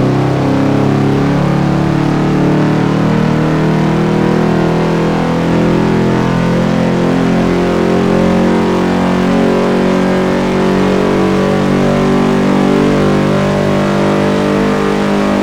Index of /server/sound/vehicles/lwcars/dodge_daytona
fourth_cruise.wav